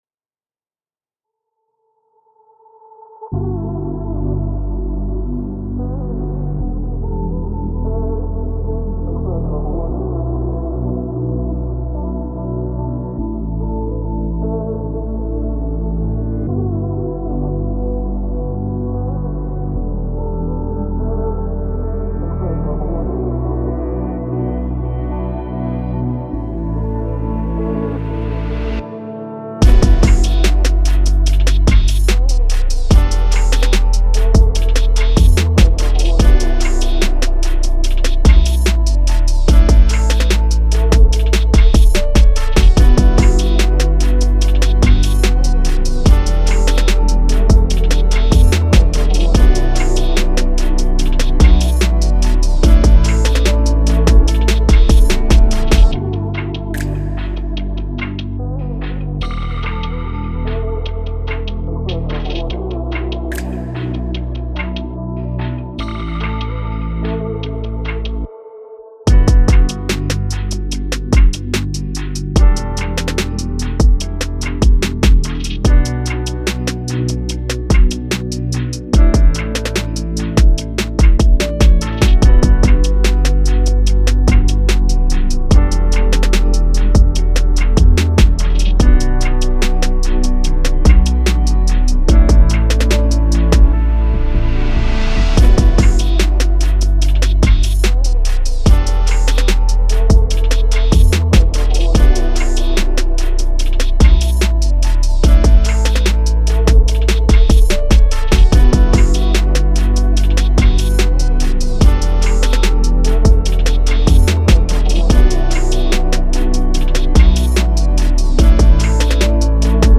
Pop
B min